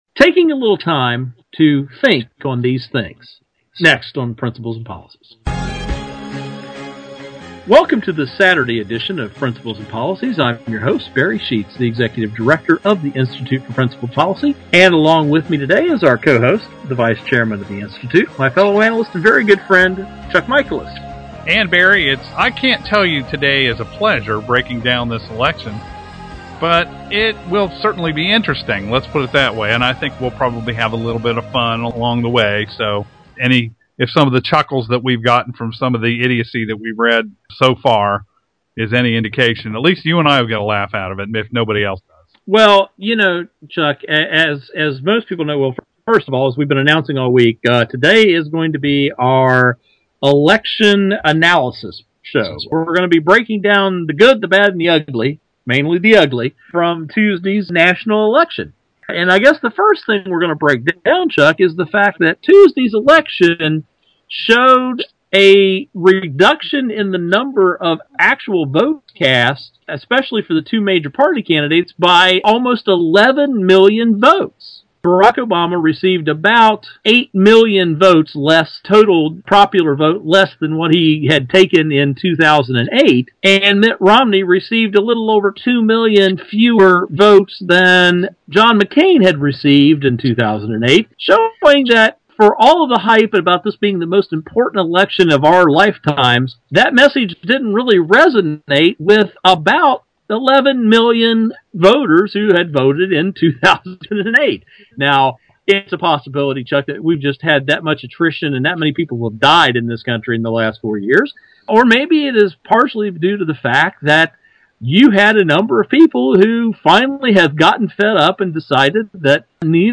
Our Principles and Policies radio show for Saturday November 10, 2012.